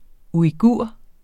Udtale [ uiˈguɐ̯ˀ ]